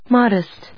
音節mod・est 発音記号・読み方
/mάdɪst(米国英語), mˈɔdɪst(英国英語)/